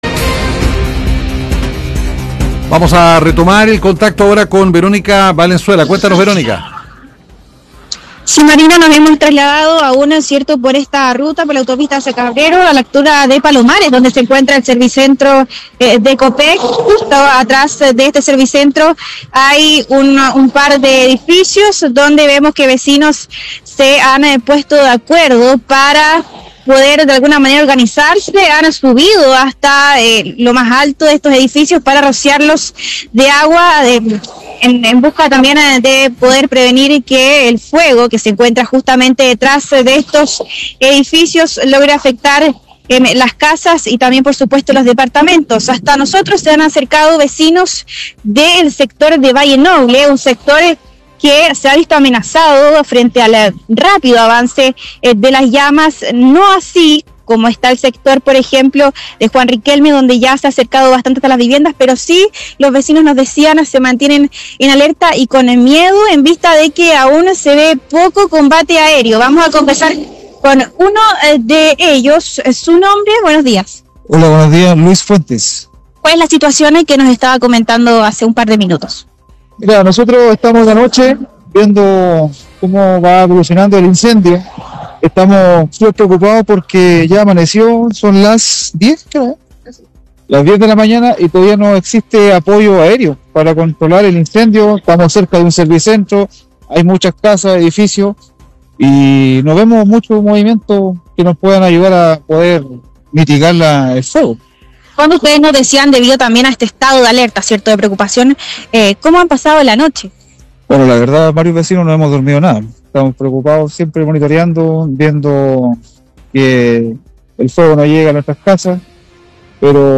Posteriormente, conversamos con vecinos del sector Palomares: